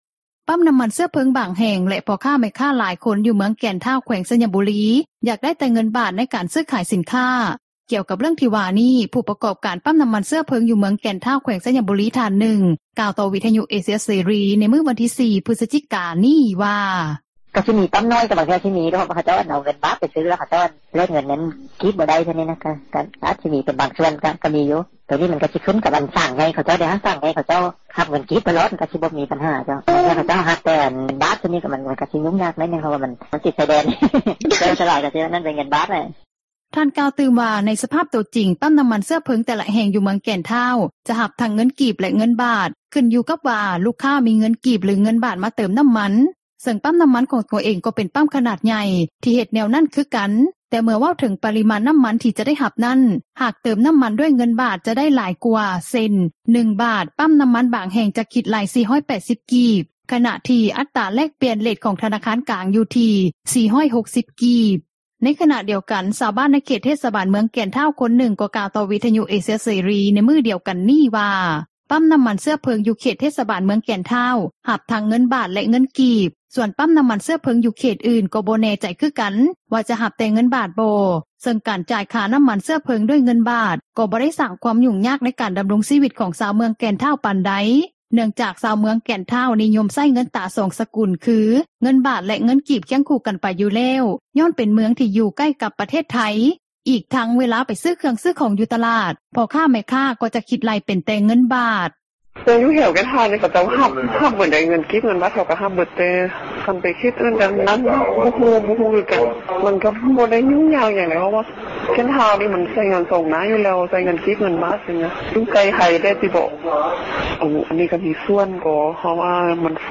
ຊື້-ຂາຍ ສິນຄ້າ ຢູ່ເມືອງແກ່ນທ້າວ ບາງສ່ວນ ໃຊ້ເງິນບາທໄທຍ — ຂ່າວລາວ ວິທຍຸເອເຊັຽເສຣີ ພາສາລາວ